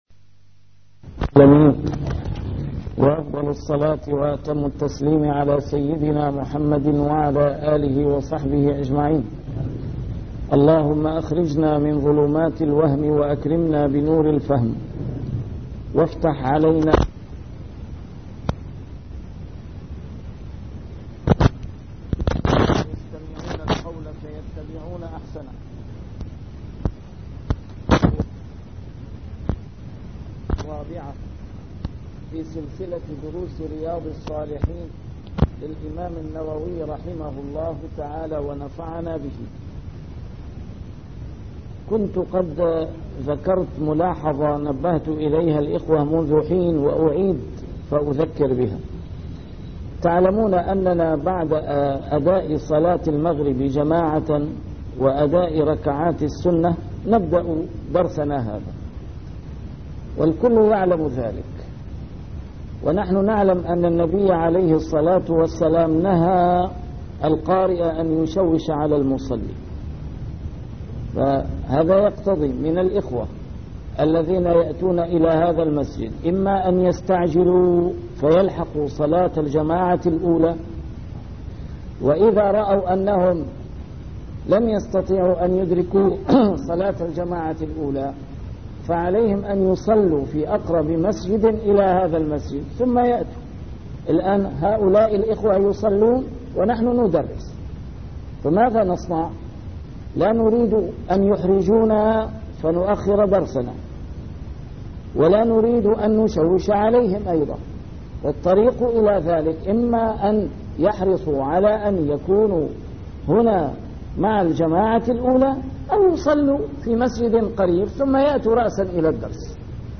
A MARTYR SCHOLAR: IMAM MUHAMMAD SAEED RAMADAN AL-BOUTI - الدروس العلمية - شرح كتاب رياض الصالحين - 409- شرح رياض الصالحين: بر أصدقاء الأب والأم